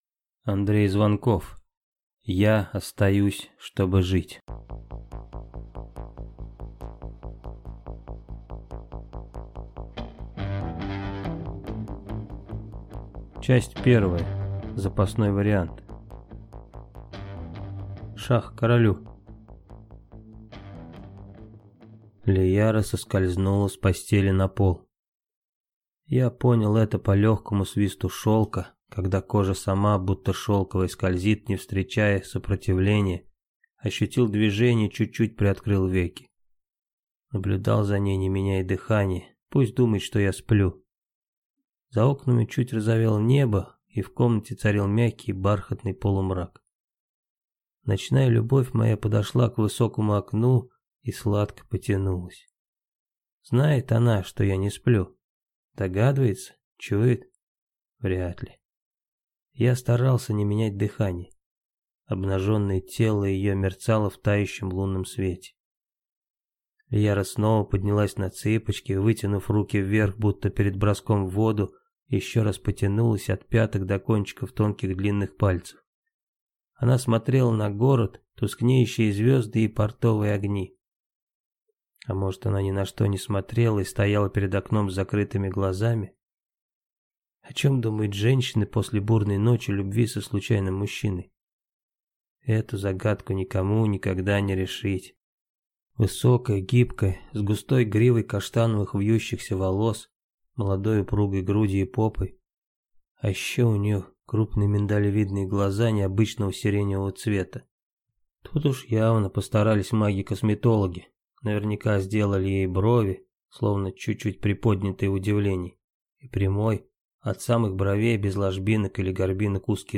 Аудиокнига Я остаюсь, чтобы жить!